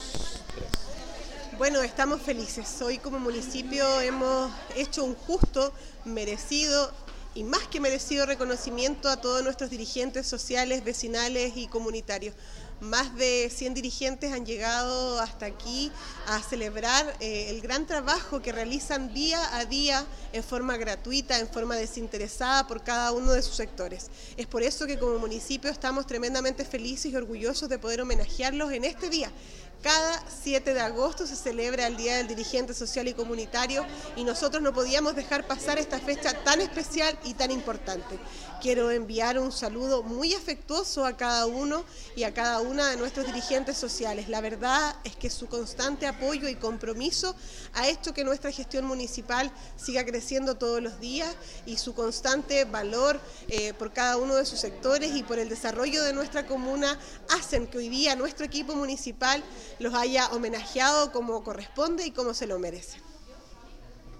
Con una actividad realizada en el sector de Tolquien, la cual estuvo encabezada por la alcaldesa Javiera Yáñez, junto a los concejales Camilo Maldonado, Luis Aravena, Ramón Paillacar, Nancy Oyarzún y Juan Muñoz, y funcionarios municipales, se contó con la presencia de 100 líderes comunitarios de todos los sectores de la comuna y de juntas de vecinos, comités de agua potable, comités de capilla, clubes deportivos, centro de padres, de turismo y medio ambiente, clubes de adultos mayores, entre otros muchos más.
Al respecto, la alcaldesa Javiera Yáñez indicó: